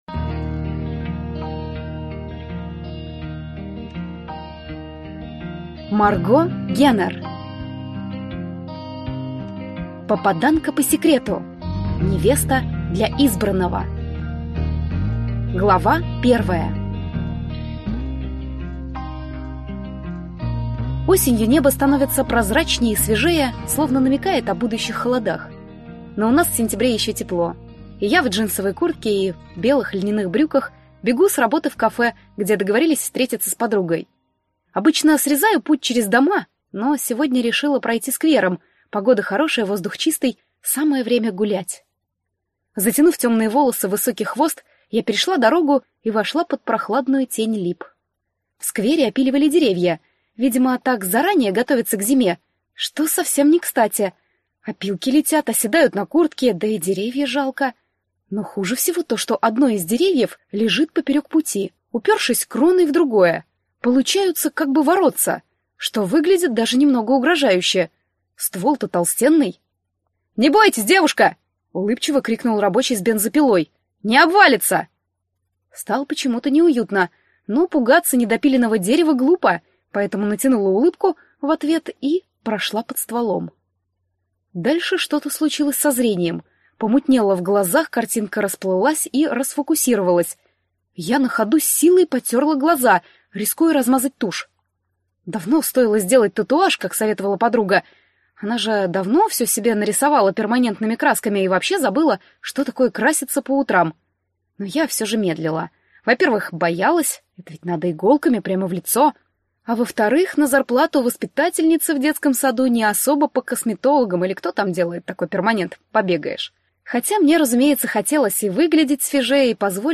Аудиокнига Попаданка по секрету. Невеста для избранного | Библиотека аудиокниг